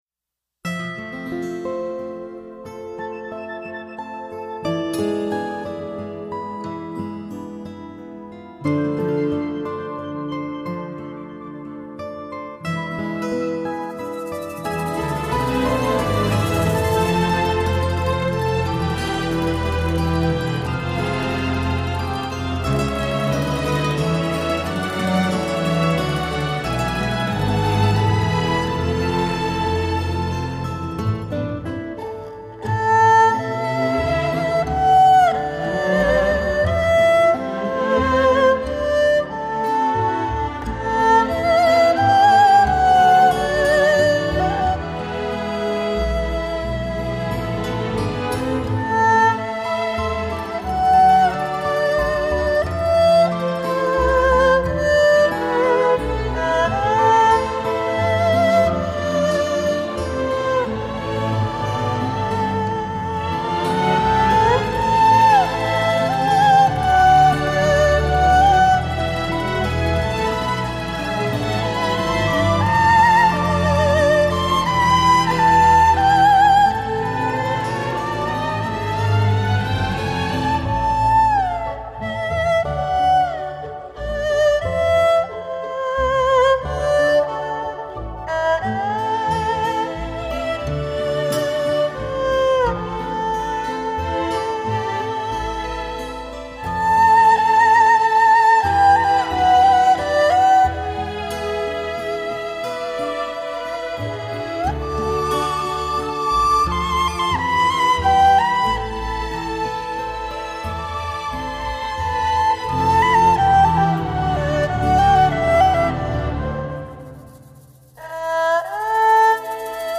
可音色缠绵可柔美伤怀可委婉含蓄……
百转千回的旋律，